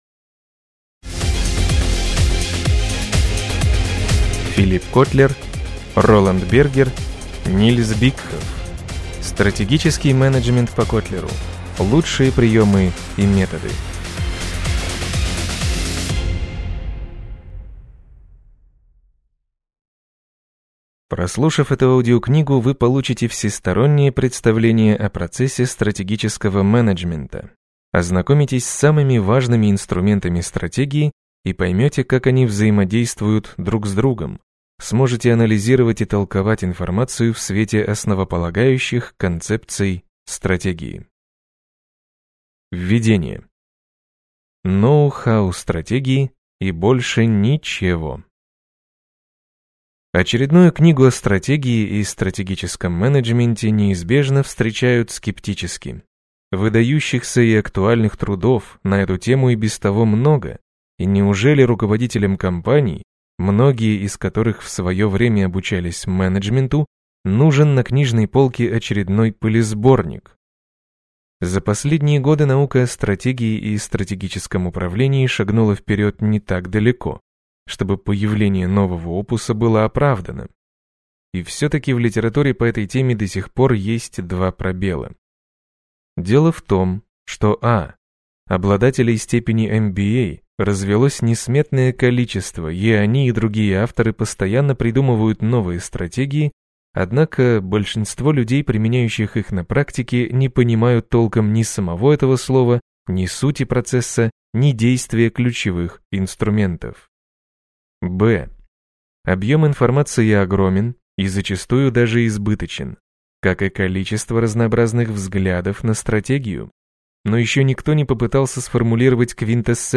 Аудиокнига Стратегический менеджмент по Котлеру: Лучшие приемы и методы | Библиотека аудиокниг